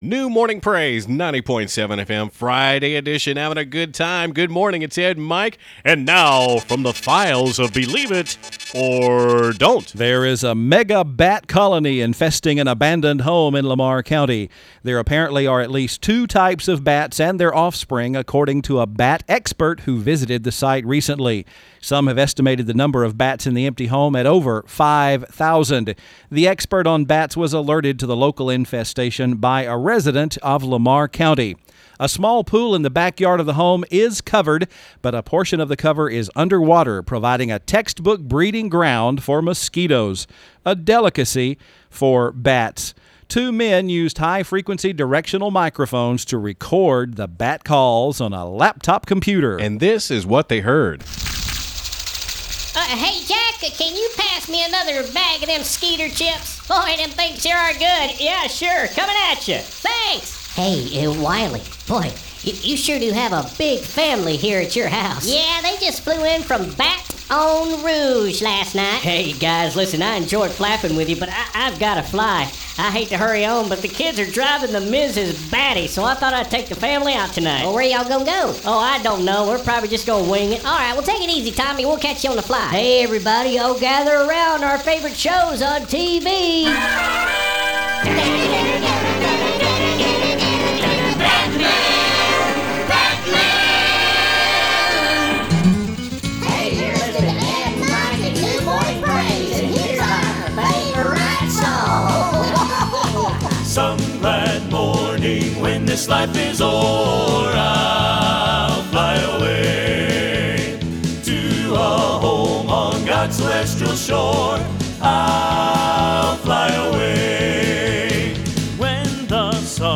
a spoof of Batman